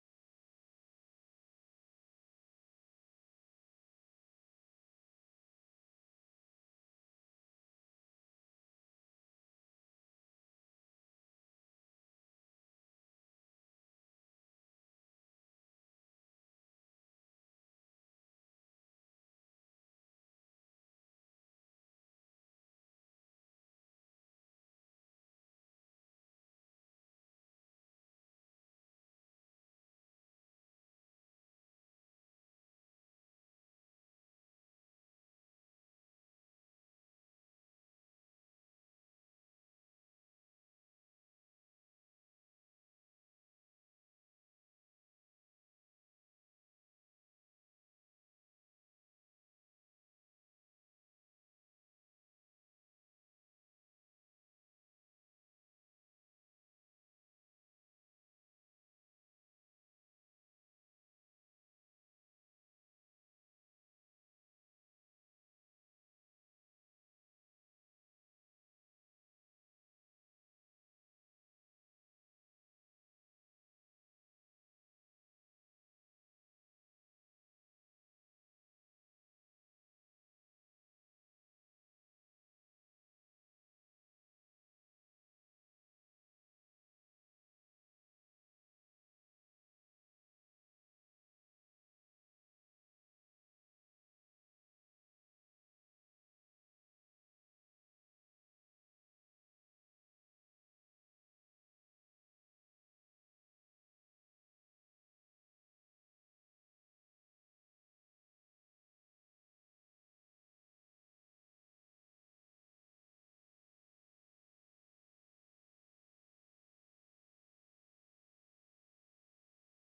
2025 • 33.68 MB Listen to Sermon Download this Sermon Download this Sermon To download this sermon